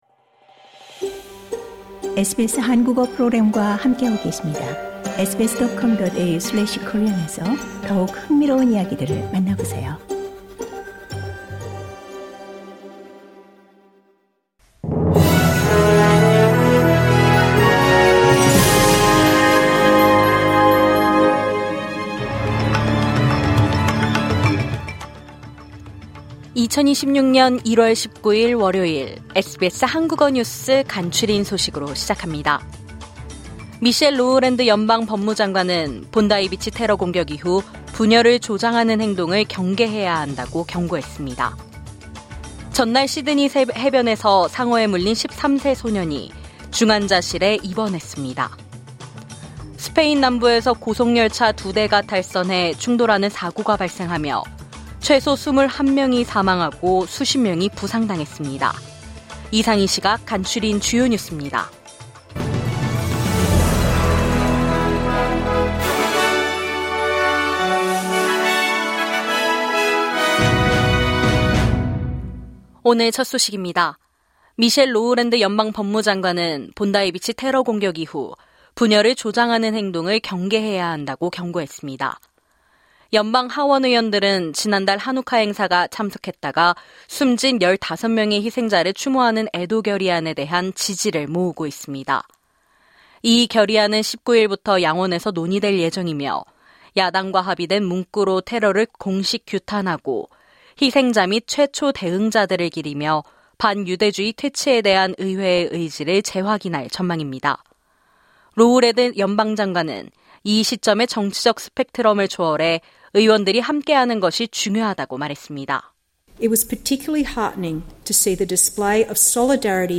환율 1 AUD = 0.66 USD = 986 KRW (오후 3시 기준) 내일의 날씨 시드니: 소나기. 18도-26도 멜번: 맑음. 18도-28도 캔버라: 흐림. 11도-27도 브리즈번: 흐림. 20도-29도 애들레이드: 흐림. 16도-31도 퍼스: 맑음. 21도-39도 호바트: 맑음. 14도-25도 다윈: 소나기. 25도-31도 상단의 오디오를 재생하시면 뉴스를 들으실 수 있습니다.